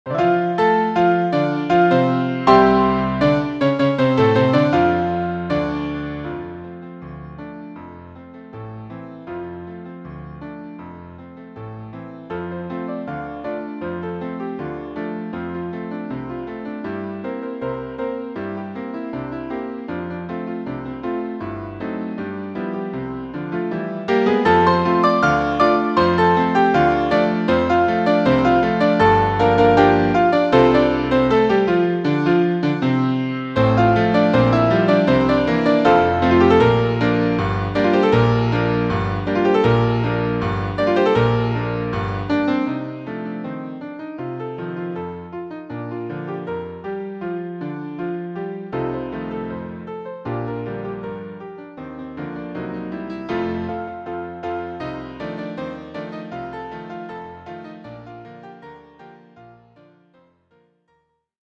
【編成】ピアノ４手連弾(1台4手)